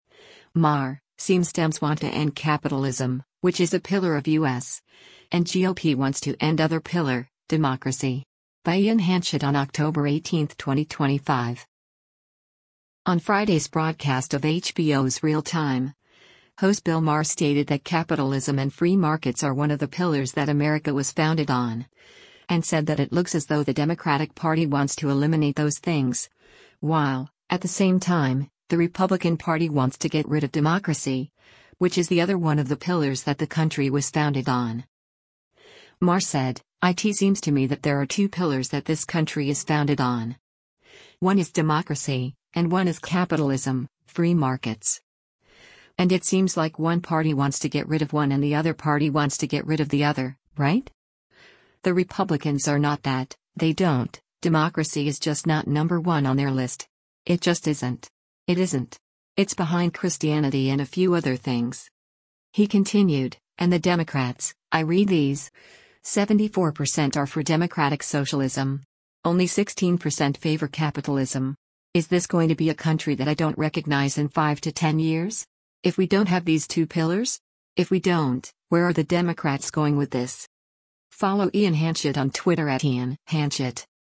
On Friday’s broadcast of HBO’s “Real Time,” host Bill Maher stated that capitalism and free markets are one of the pillars that America was founded on, and said that it looks as though the Democratic Party wants to eliminate those things, while, at the same time, the Republican Party wants to get rid of democracy, which is the other one of the pillars that the country was founded on.